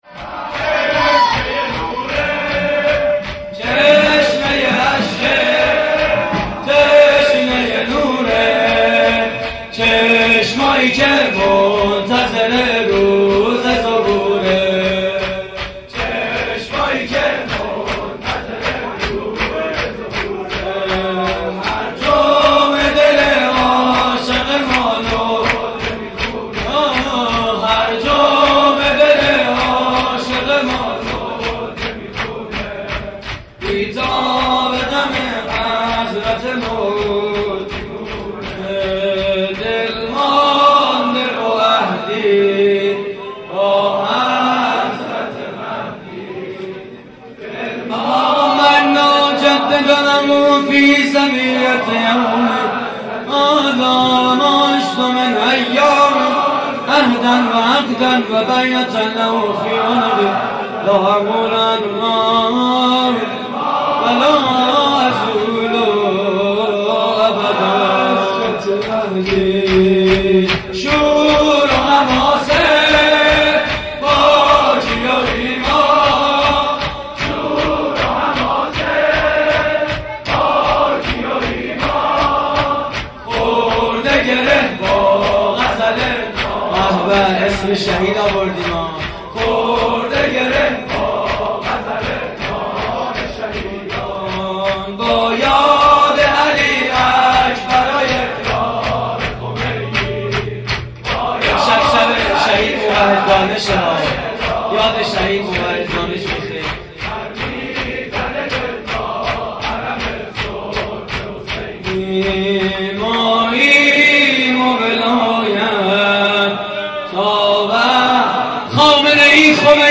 سرود هیئت: چشمه‌ی اشکه؛ پخش آنلاین |